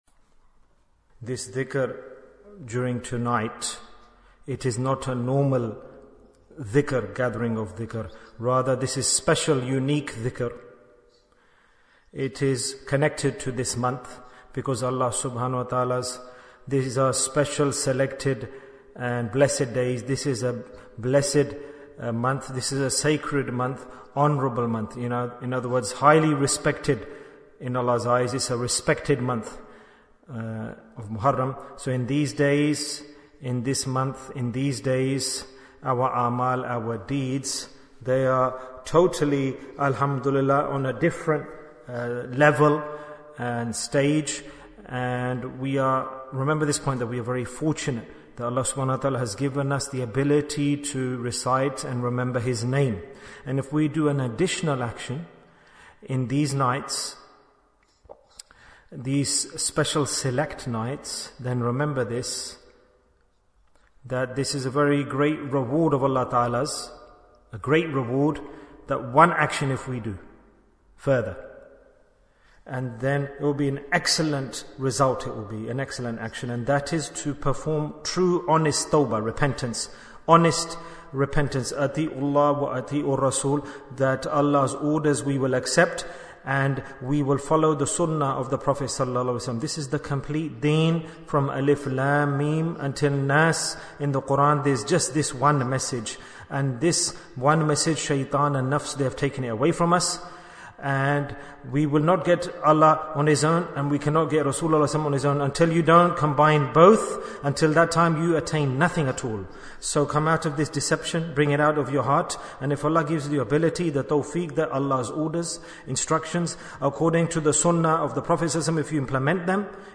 Talk before Dhikr 15 minutes11th July, 2024